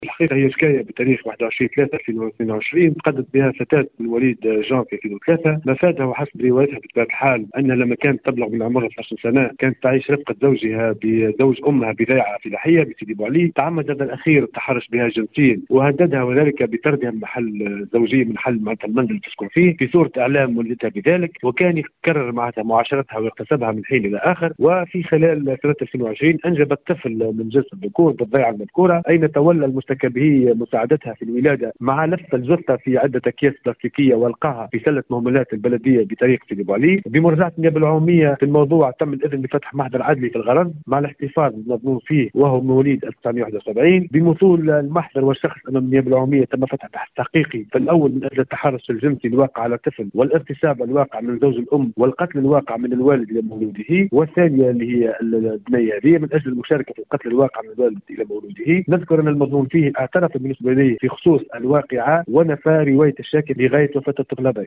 في تصريح ل”ام اف ام”